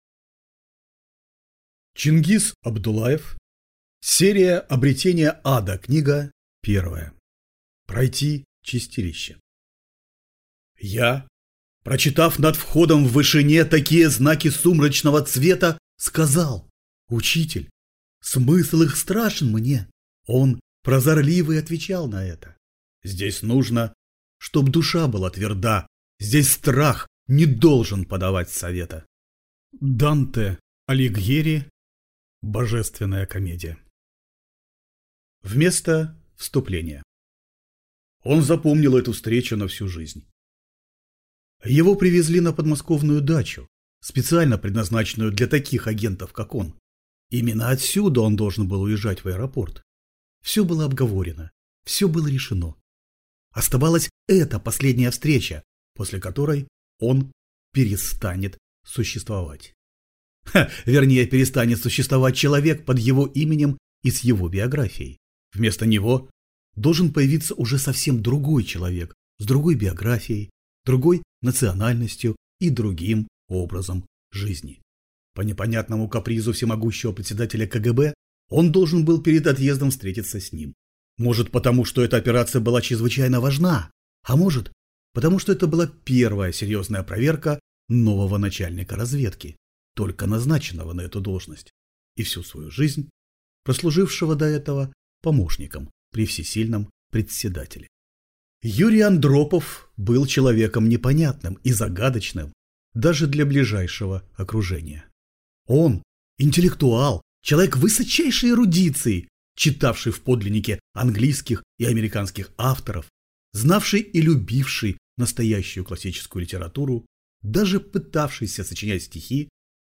Аудиокнига Пройти чистилище | Библиотека аудиокниг